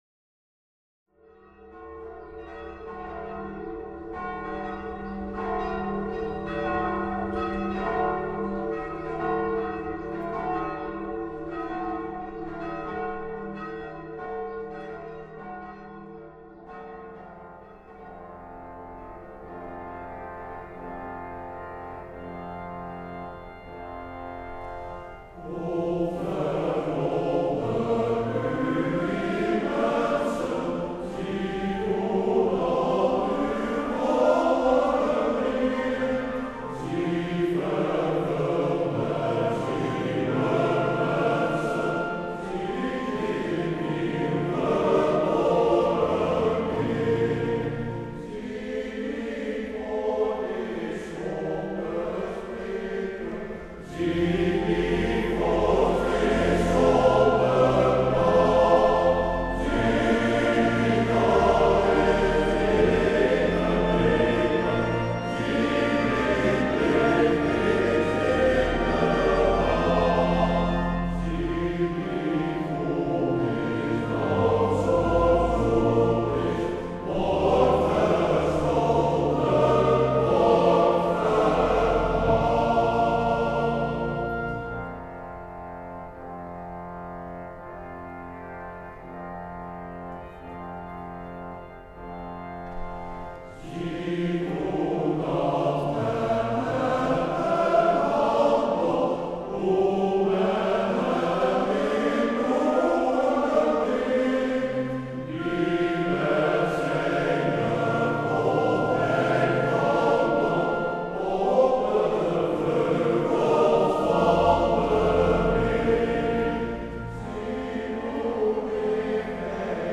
Door de verenigde Zangers uit Barendrecht.